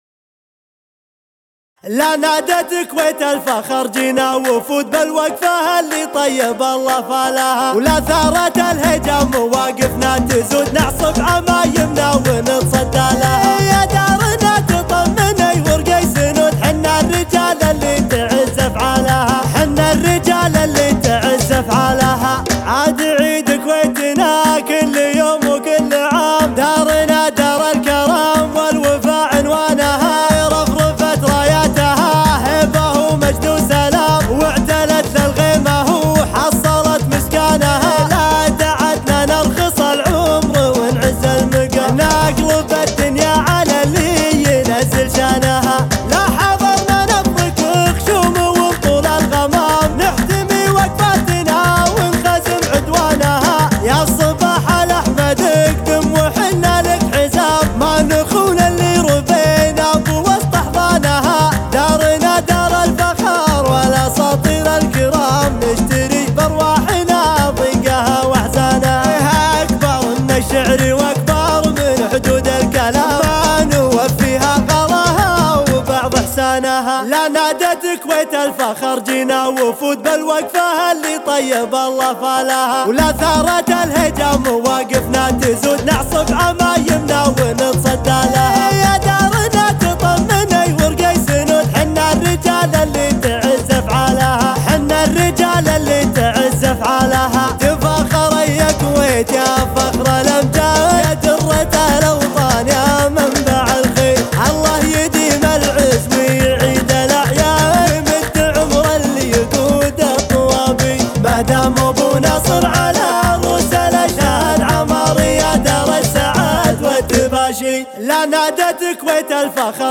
SheLah